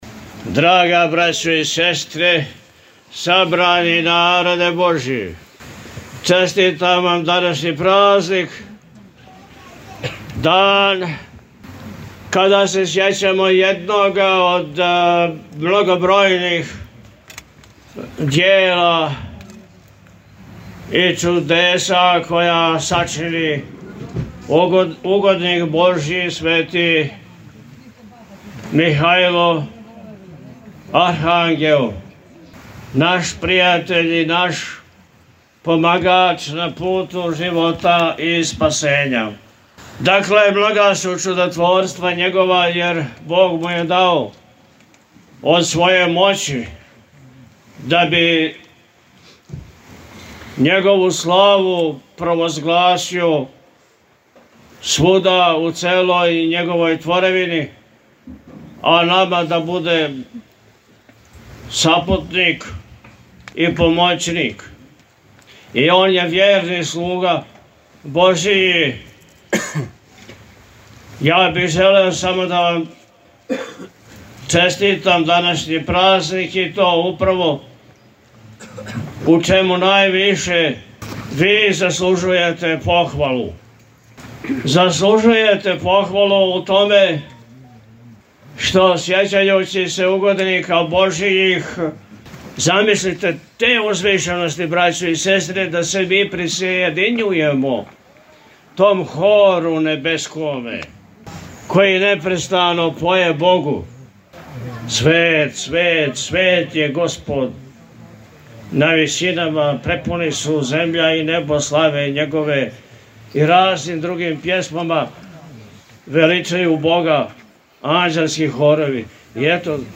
Бесједа-владика-Атанасије-слава-Манастир-Тара.mp3